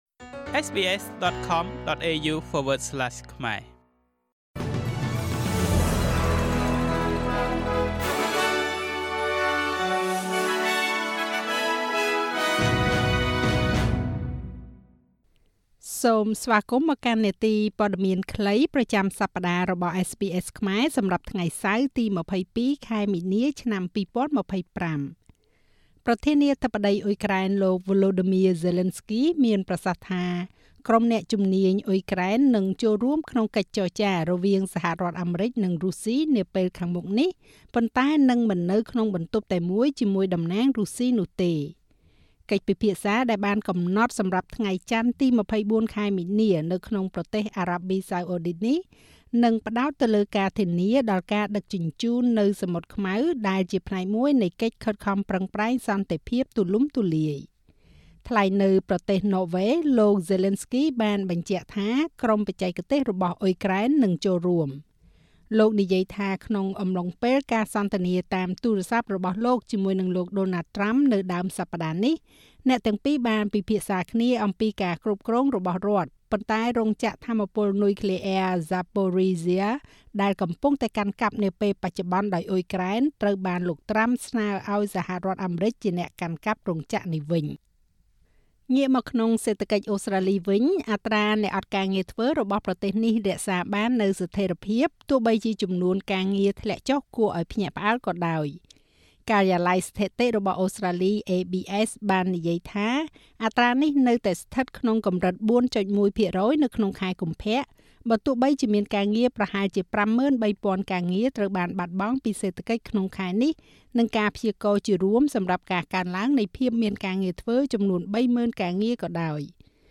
នាទីព័ត៌មានខ្លីប្រចាំសប្តាហ៍របស់SBSខ្មែរ សម្រាប់ថ្ងៃសៅរ៍ ទី២២ ខែមីនា ឆ្នាំ២០២៥